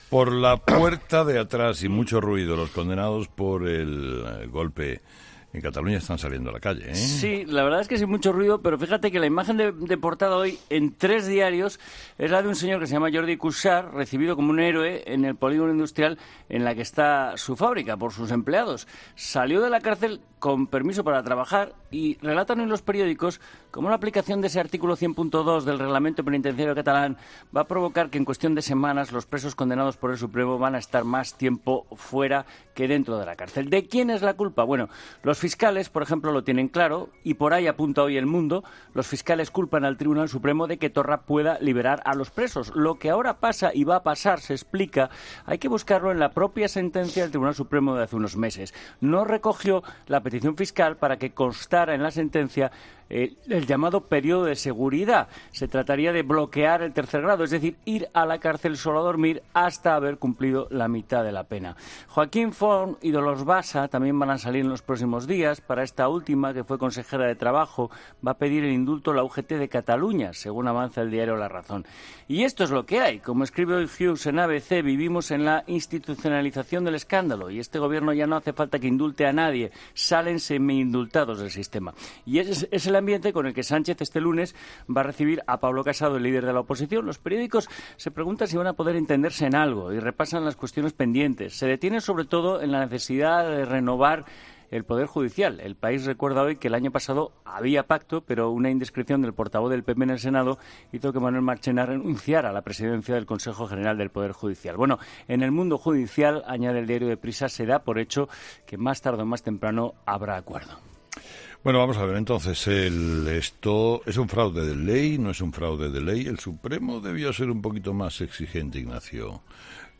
La tertulia de 'Herrera en COPE' analiza la salida de los presos del procés de la cárcel